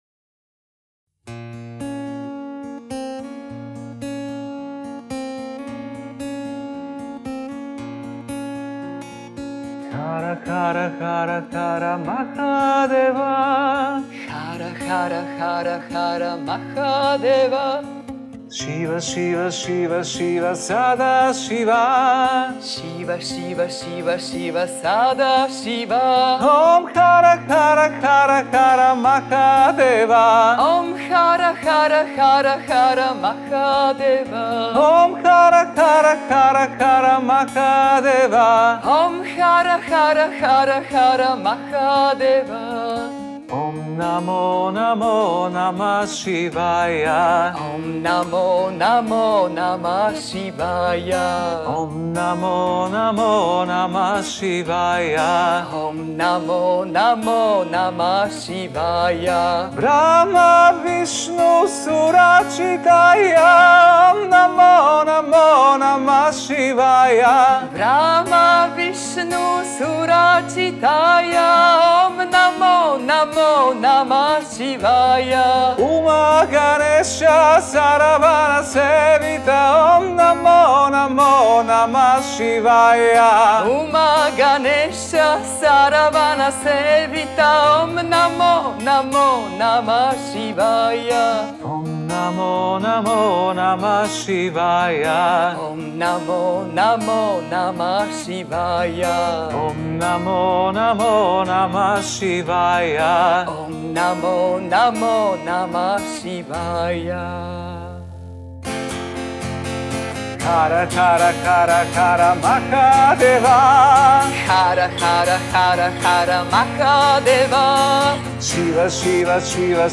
Mantras and Kirtans in praise of Lord Shiva.
Recordings from Yoga Vidya Ashram Germany.
Group_Mudita-Hara_Hara_Hara_Hara_Mahadeva.mp3